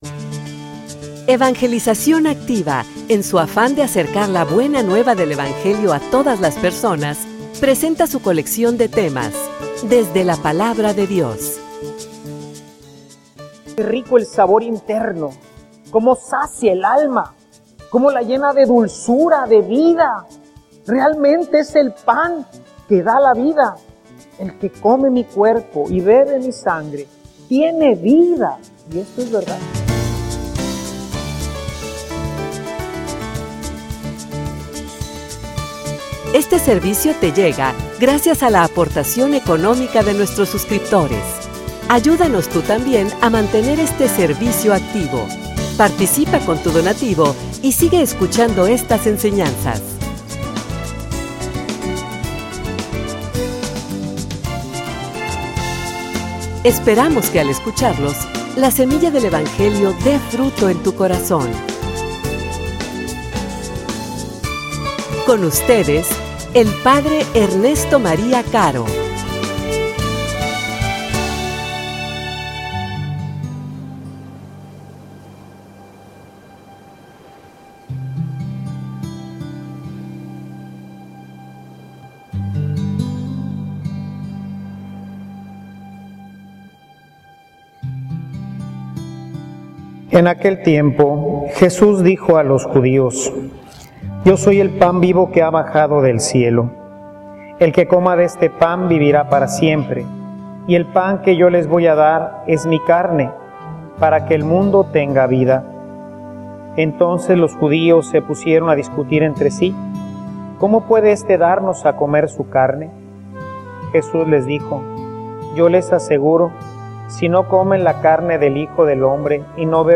homilia_Una_fiesta_por_renovar.mp3